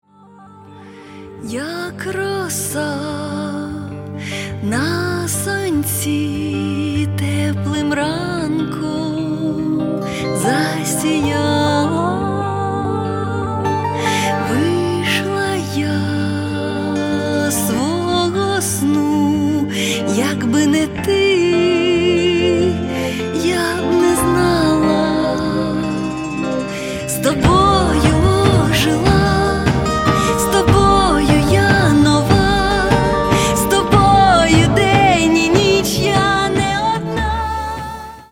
• Качество: 128, Stereo
громкие
женский вокал
спокойные
красивая мелодия
фолк